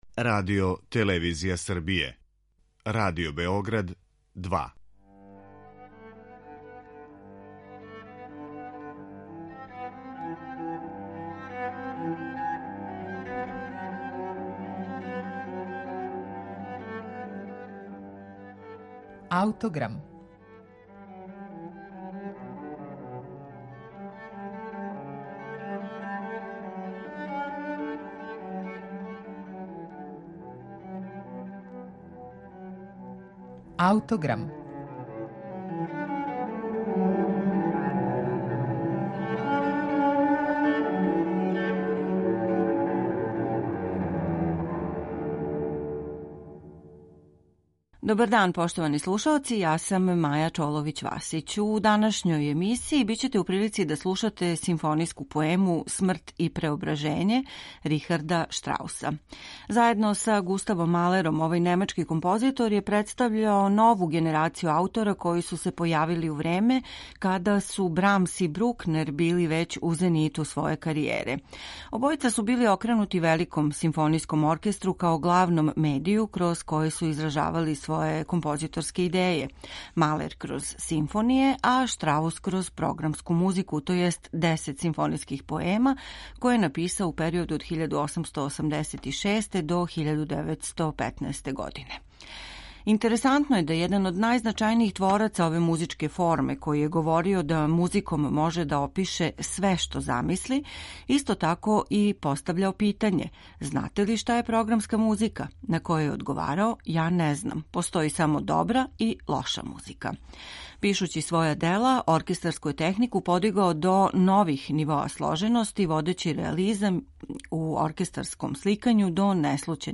Бостонски симфонијски оркестар под управом Андриса Нелсонса изводи композицију „Смрт и преображење”, коју је написао Рихард Штраус.
Прва симфонијска поема овог композитора - за коју је приложио детаљан програм и чијом је премијером 1890. године сам дириговао - сматра се ремек-делом у овом сегметну његовог опуса.